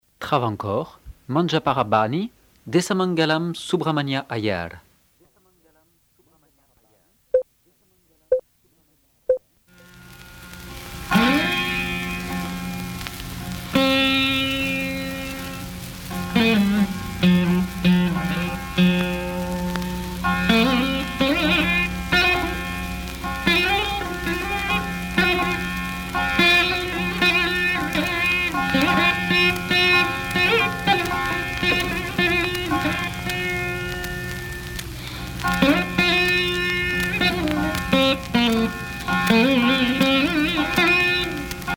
Alap
Musique carnatique
Pièce musicale inédite